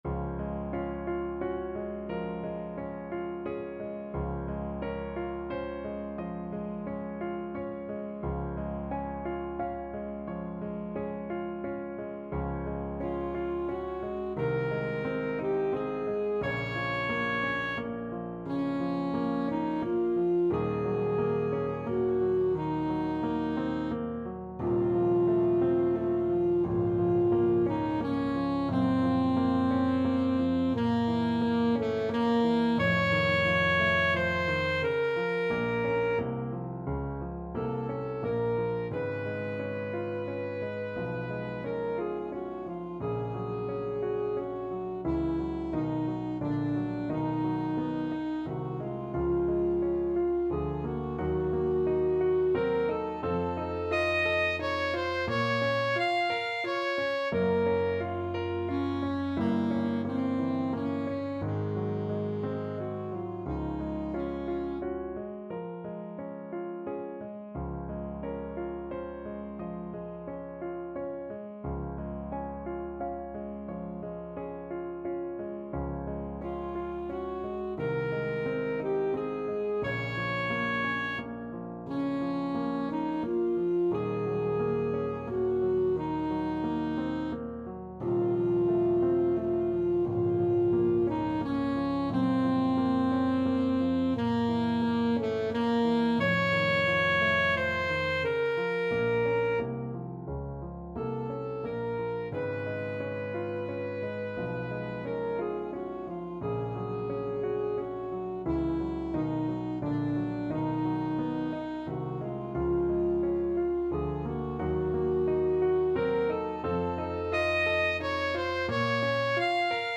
Alto Saxophone
~ = 88 Andante
Classical (View more Classical Saxophone Music)